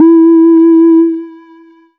基本正弦波 " 基本正弦波G1
它是一个基本的正弦波形，上面有一些小的延迟。
采样名称中的音符（C、E或G）确实表明了声音的音高。这个声音是用Reaktor的用户库中的Theremin模拟合奏制作的。之后，在Cubase SX中应用了归一化和淡化。
标签： 基本波形 Reaktor的 正弦 多重采样
声道立体声